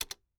pause-retry-click.mp3